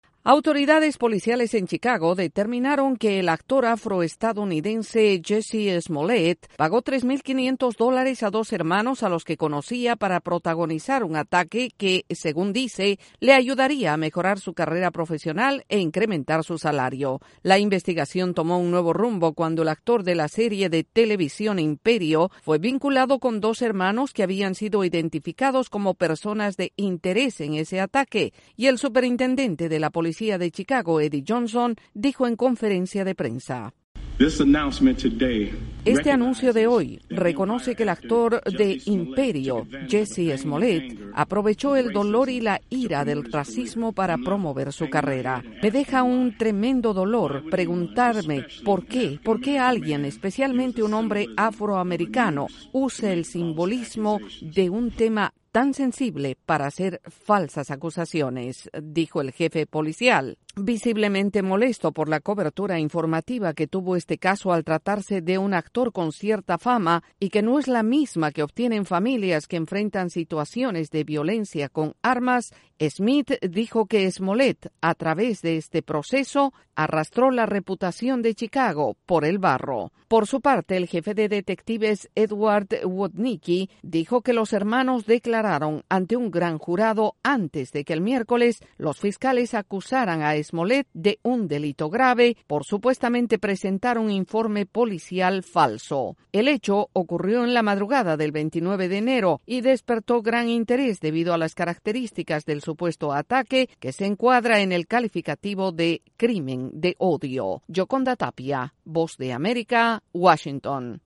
Autoridades en Chicago determinaron que el actor Jussie Smollett montó personalmente la trama del supuesto ataque racista. Desde la Voz de América en Washington informa